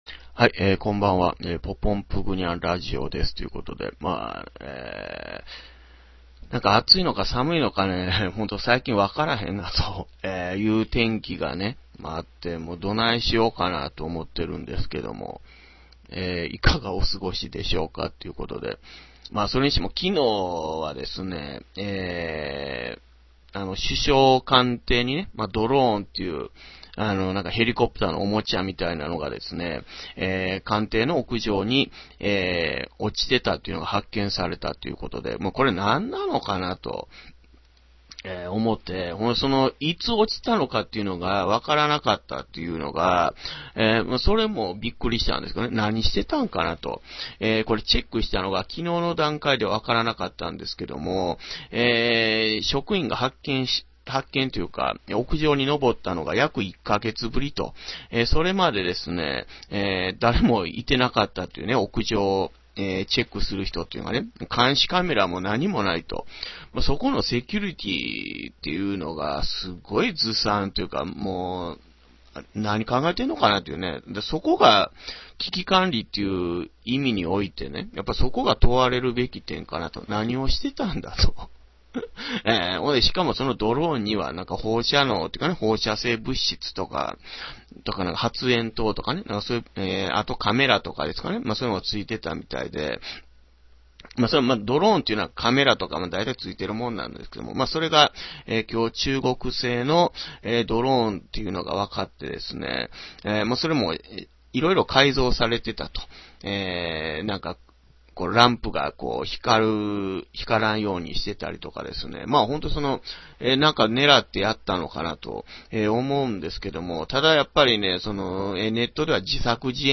(music)ニュースとトランペットとぽぽんぷぐにゃん